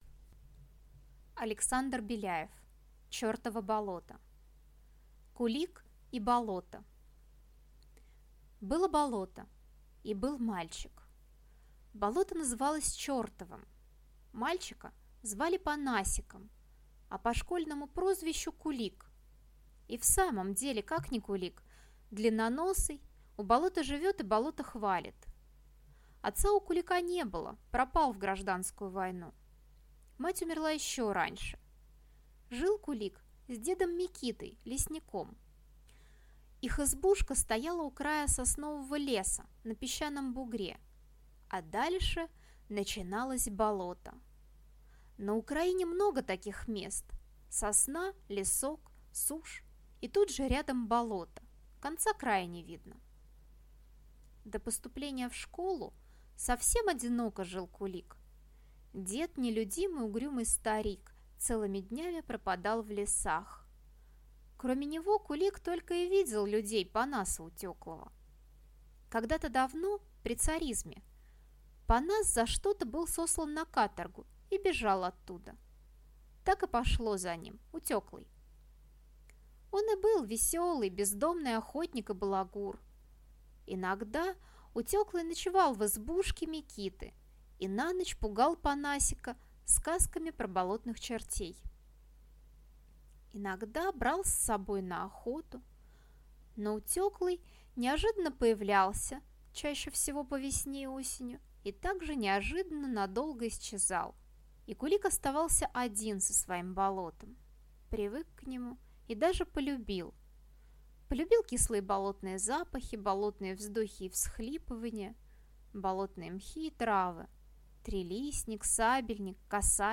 Аудиокнига Чертово болото | Библиотека аудиокниг